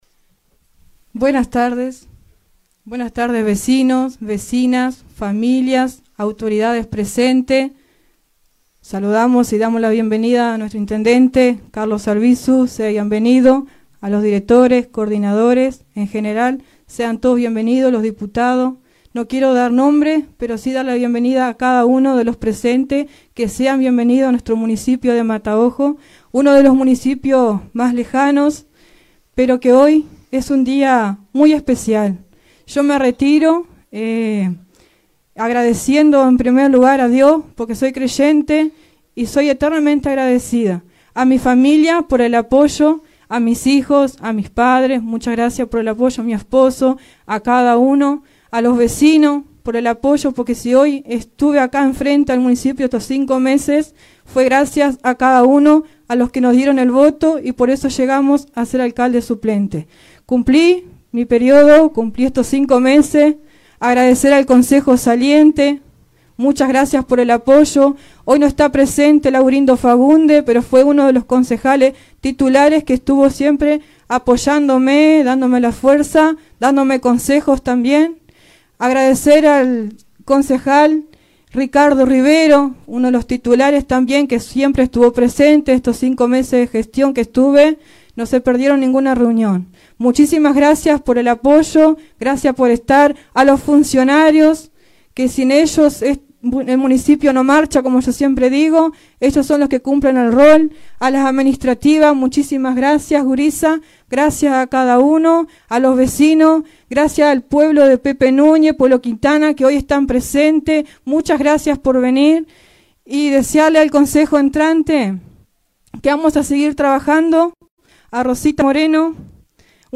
La alcaldesa saliente, Grecy Dutra, inició la ceremonia con palabras de agradecimiento y reflexión sobre su gestión, destacando el apoyo recibido de la comunidad y el trabajo conjunto con el concejo municipal saliente.